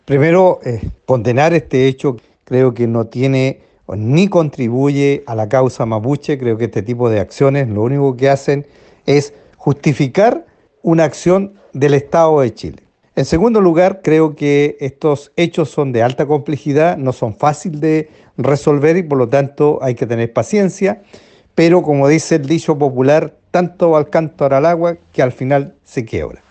alcalde-1.mp3